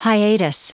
Prononciation du mot hiatus en anglais (fichier audio)
Prononciation du mot : hiatus